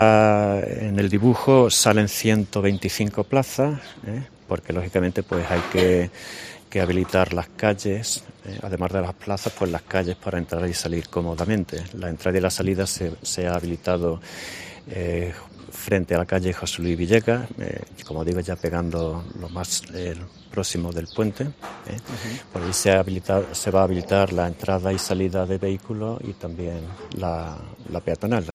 Escucha al delegado de Movilidad, Andrés Pino